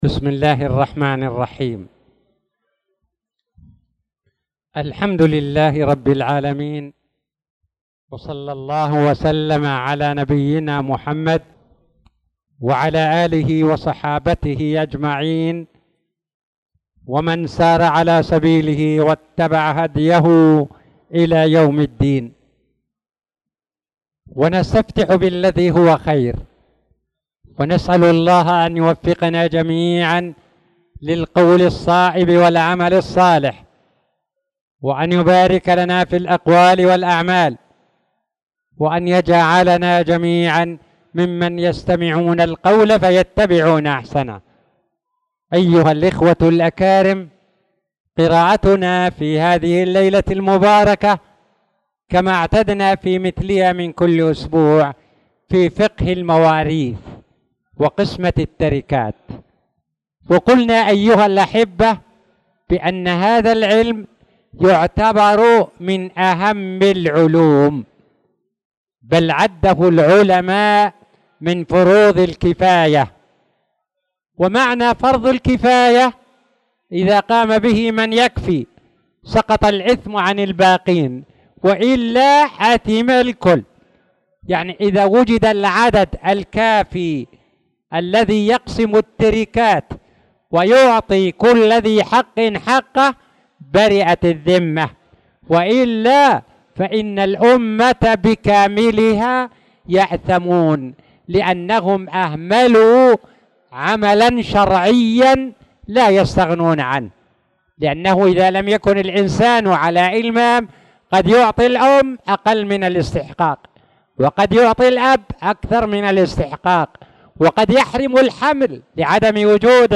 تاريخ النشر ٩ شعبان ١٤٣٧ هـ المكان: المسجد الحرام الشيخ